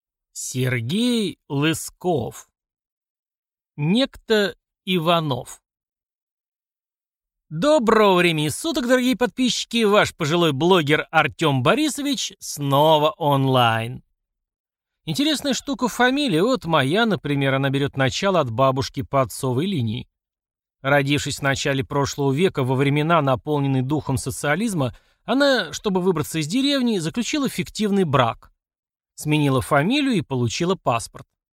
Aудиокнига Некто Иванов